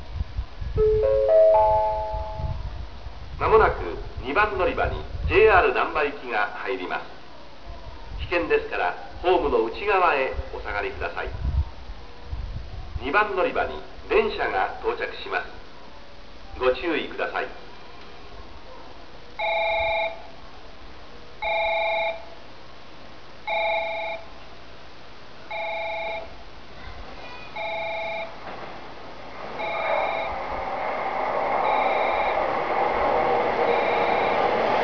96年3月22日の地下駅完成時より、下記の放送が使用されていました。 発車の際に詳細な案内があります。